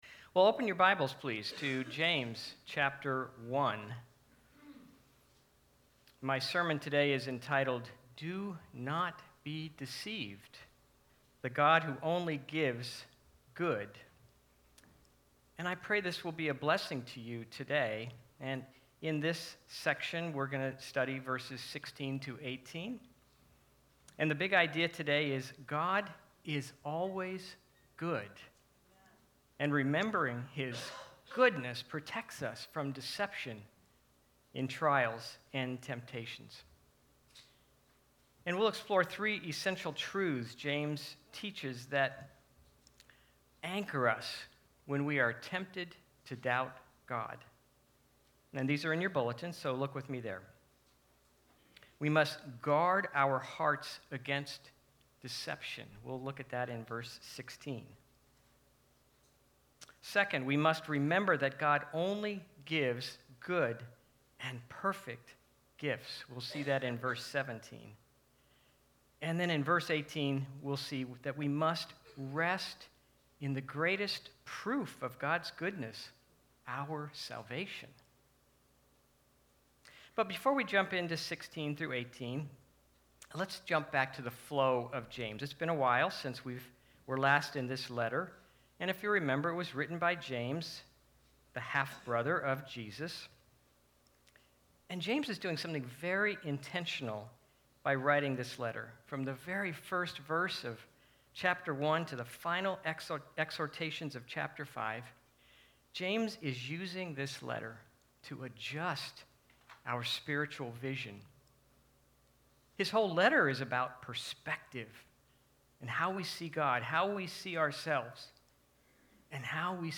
Service Type: Special Sermons